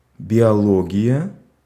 Ääntäminen
France: IPA: [bjo.lo.ʒi]